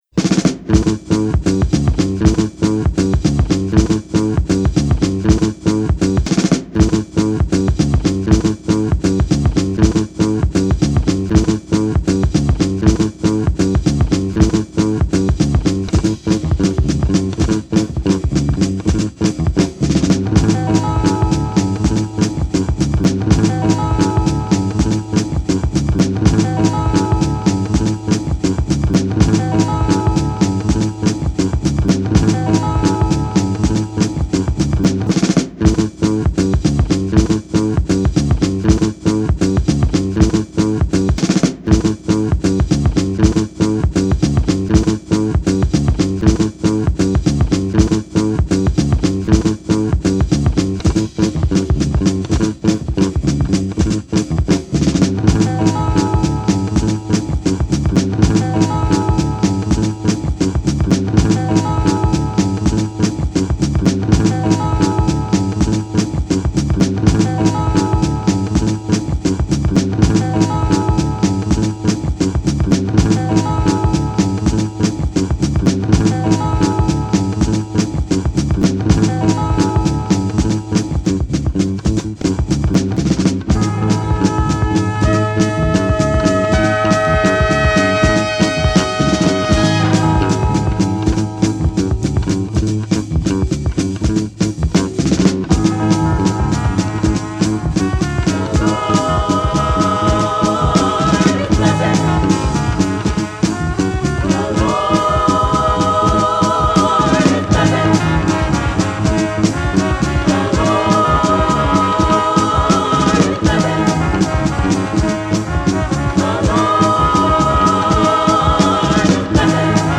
spiritual edits